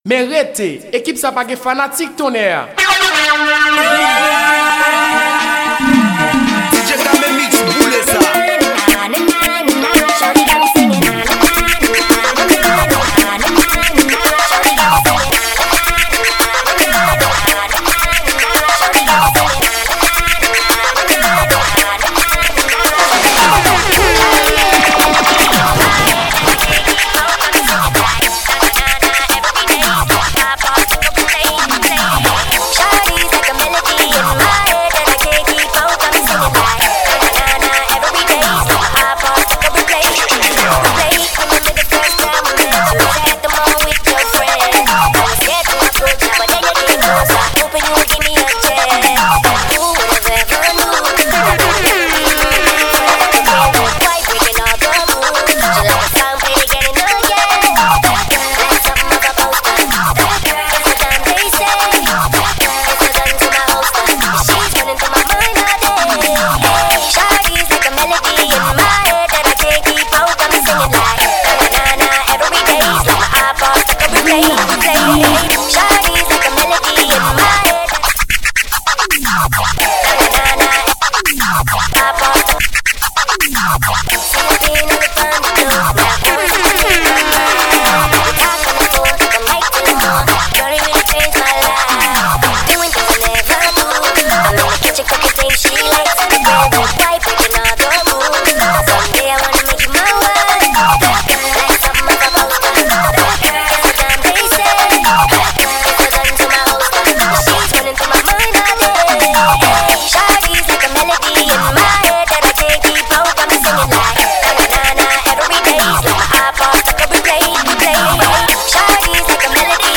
Genre: WORLD MUSIC.